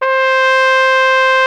Index of /90_sSampleCDs/Roland LCDP12 Solo Brass/BRS_Flugelhorn/BRS_Flugelhorn 1